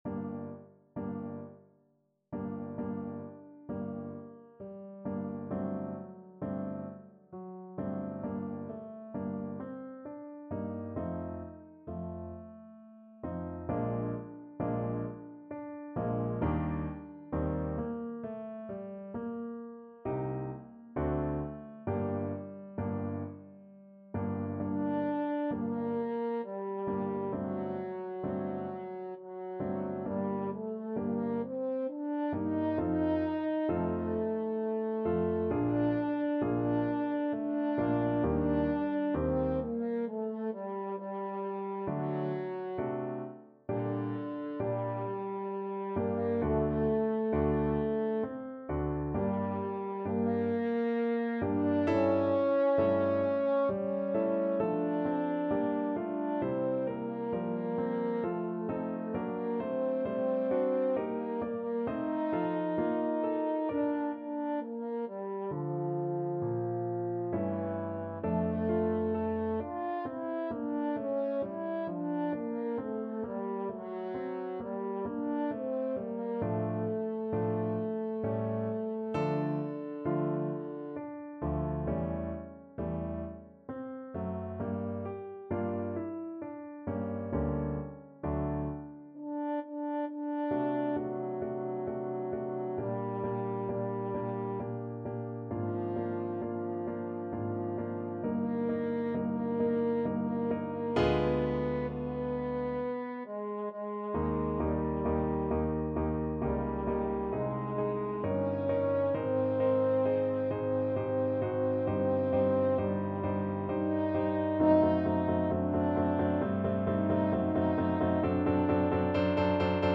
Classical
Piano Playalong